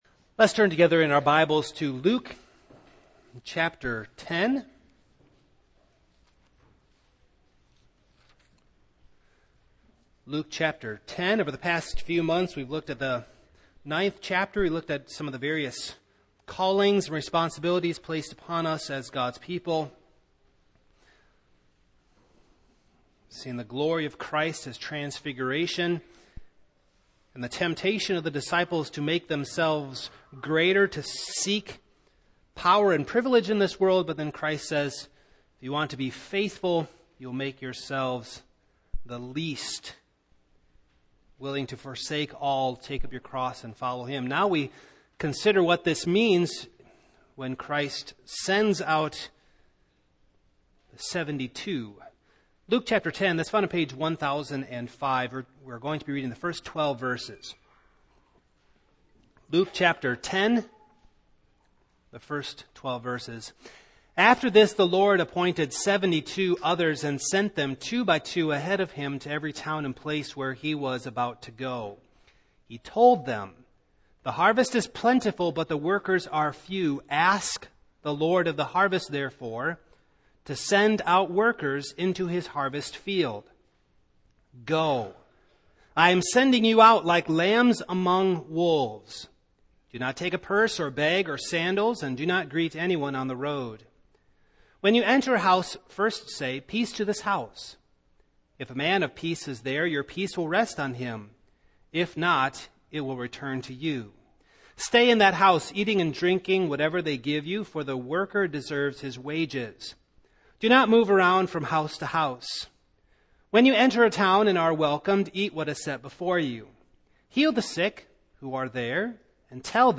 Passage: Luke 10:1-12 Service Type: Morning